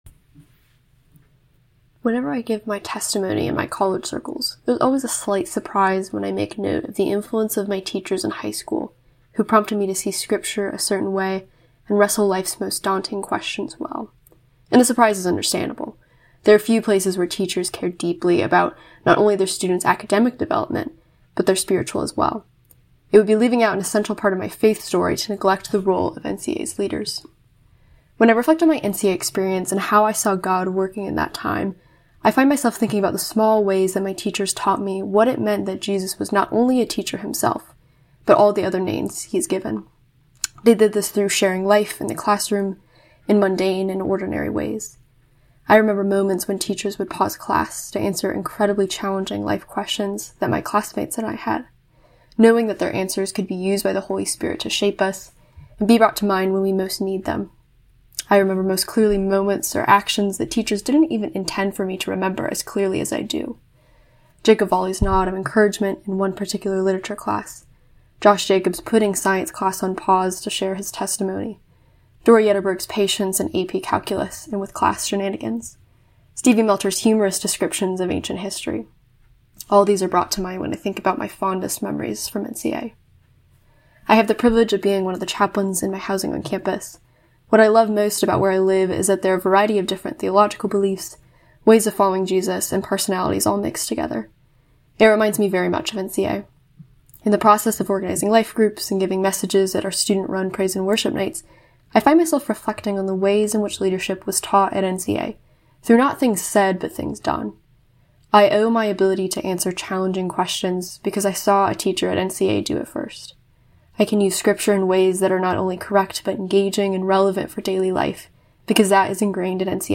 Listen below to speeches given at NCA’s 25th Year Celebration Gala about how these alumni were shaped by their NCA education and teachers.